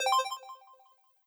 Success7a.wav